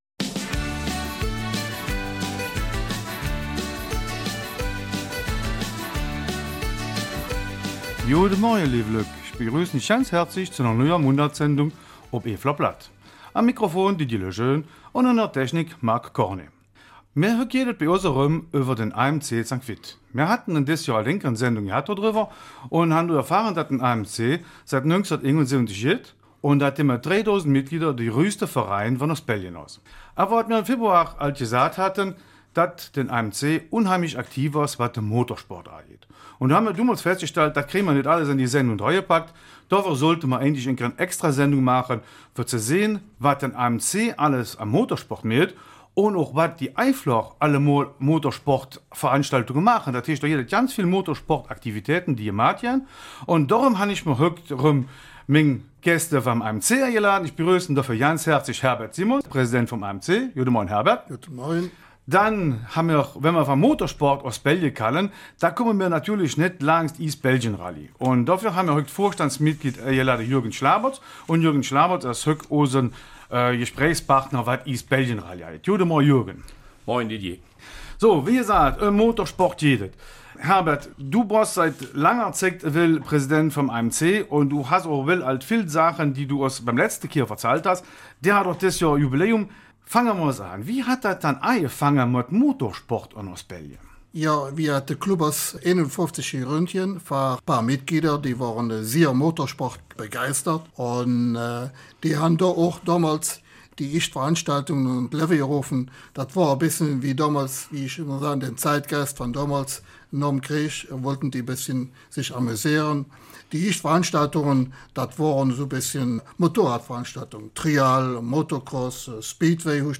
Eifeler Mundart: AMC St. Vith